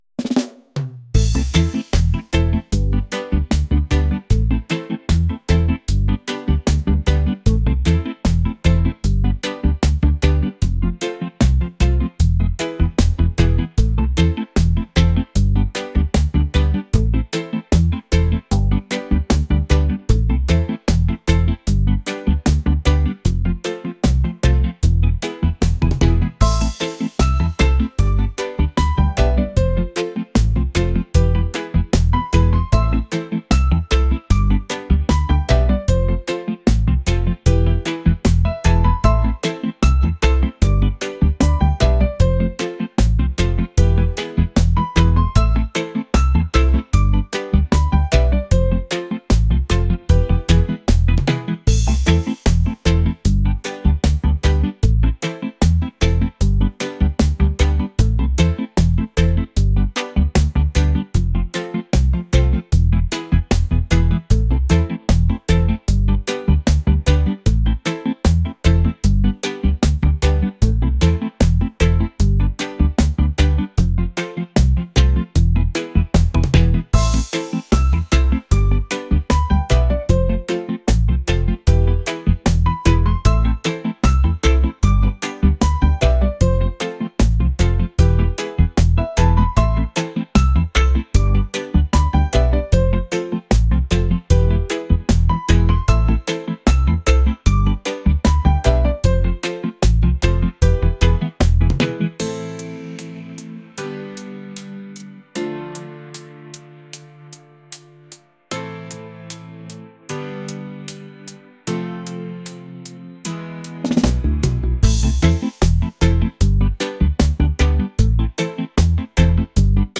reggae | lounge | acoustic